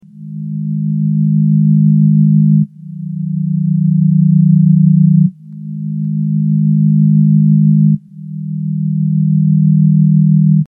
爵士合成器反转
描述：一个反转的合成器。
Tag: 90 bpm Jazz Loops Synth Loops 1.79 MB wav Key : Unknown